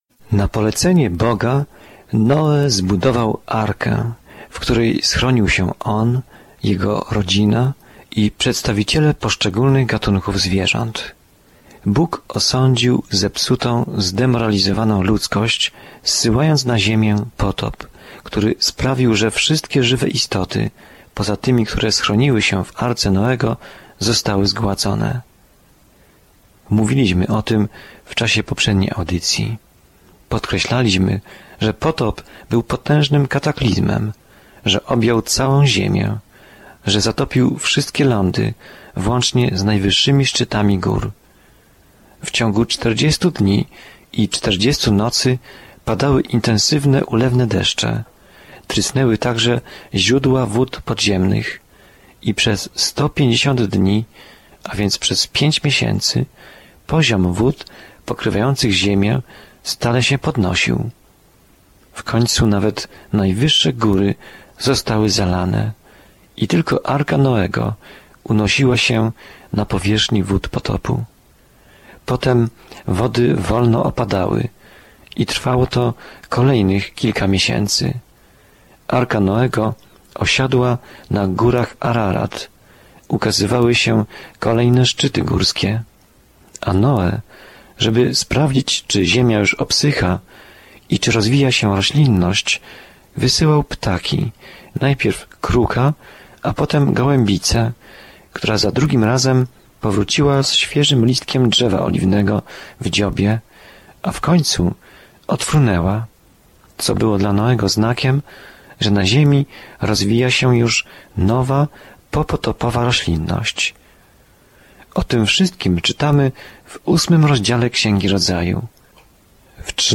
Pismo Święte Rodzaju 7:6-24 Rodzaju 8:1-12 Dzień 10 Rozpocznij ten plan Dzień 12 O tym planie Tutaj wszystko się zaczyna – wszechświat, słońce i księżyc, ludzie, relacje, grzech – wszystko. Codziennie podróżuj przez Księgę Rodzaju, słuchając studium audio i czytając wybrane wersety słowa Bożego.